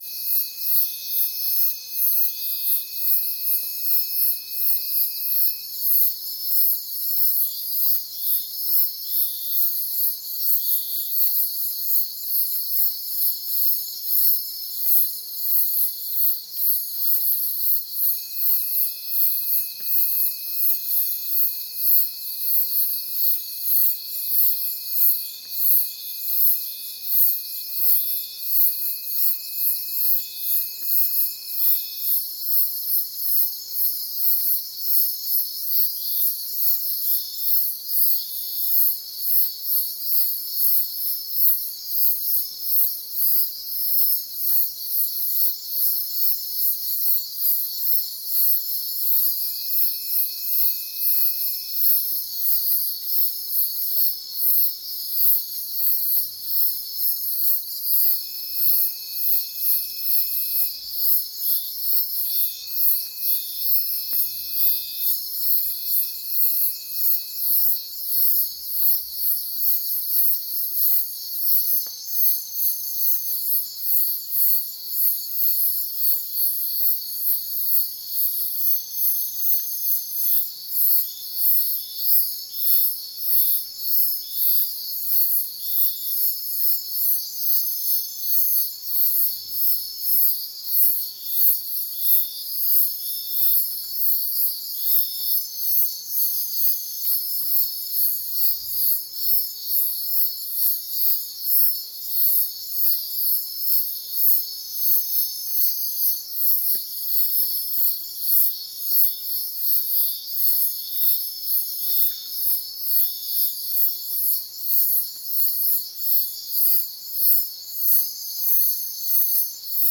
Forest Night
forest-night-1.ogg